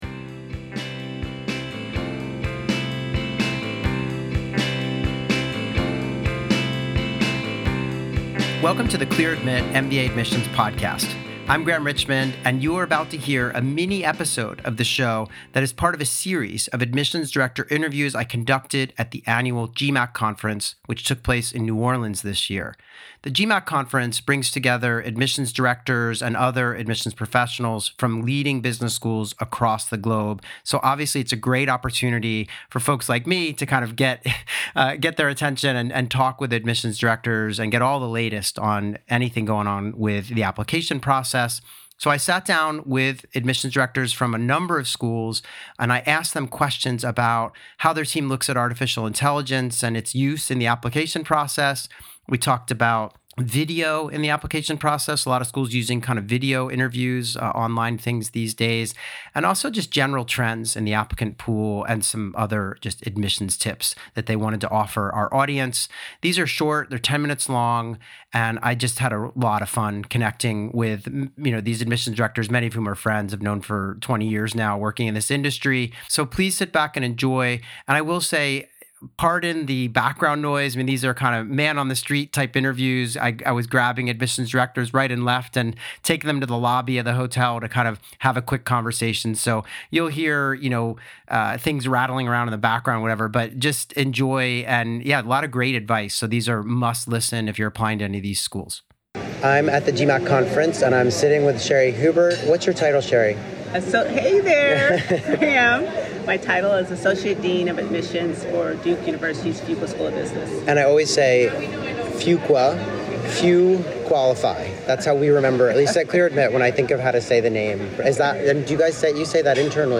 Live Admissions Q&A